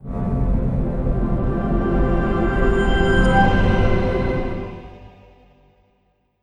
OS2 Warp 10.0 Startup.wav